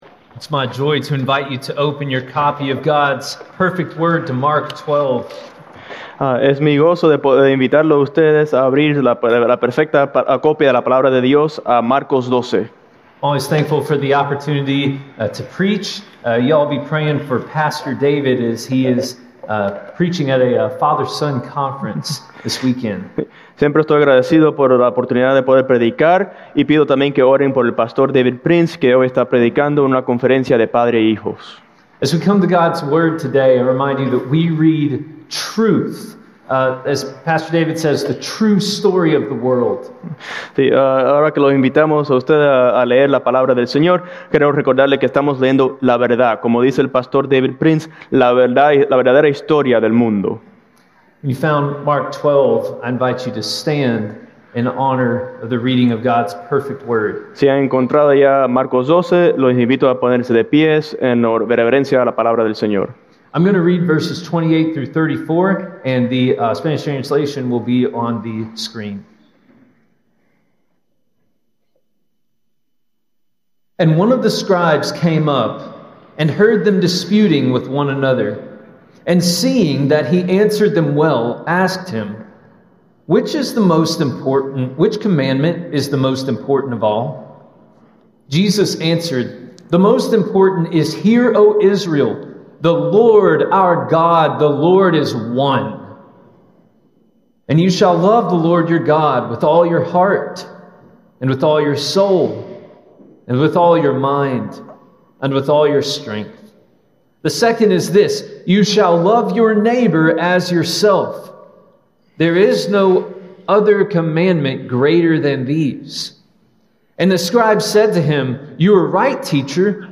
Confronting Jesus: On Morality (Mark 12:28-34) with Spanish Translation | Ashland Podcast
Sermon Audio